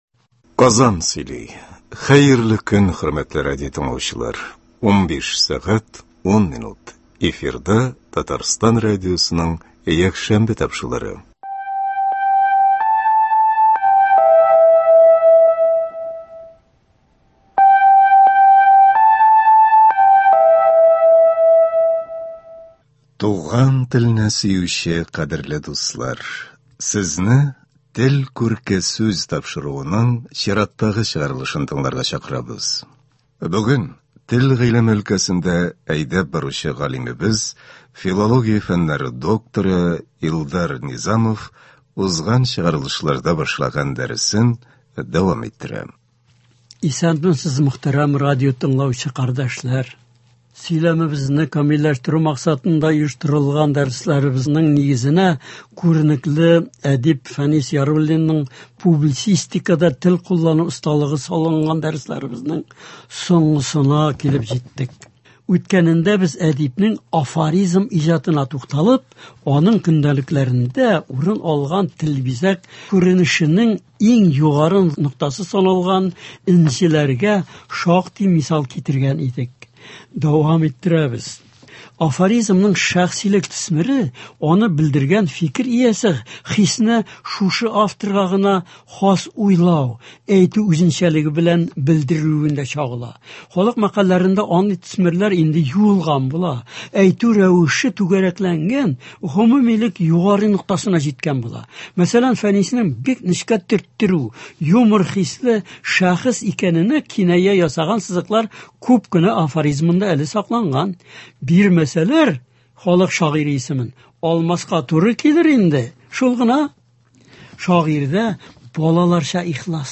Аннары, хрестоматия рәвешендә Ф. Яруллиннның “Сәясәт һәм нәфрәт” исемле хикәясеннән өзек бирелә.